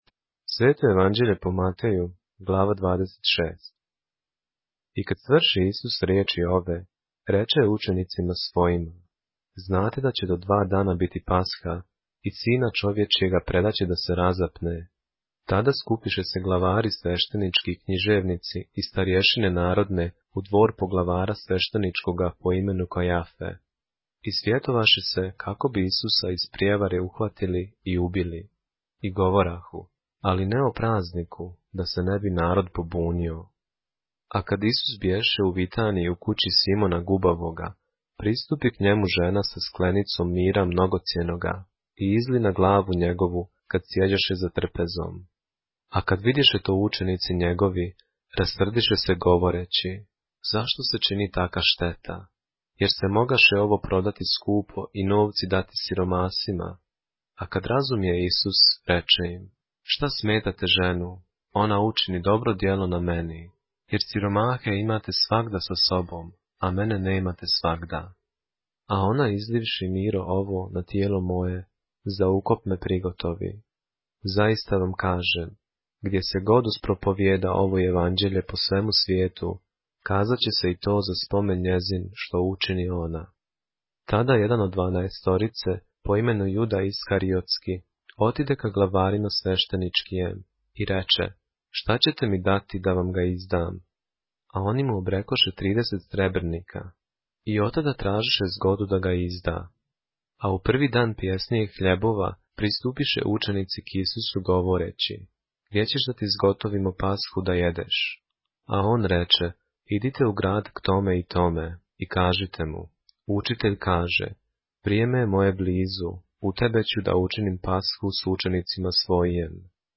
поглавље српске Библије - са аудио нарације - Matthew, chapter 26 of the Holy Bible in the Serbian language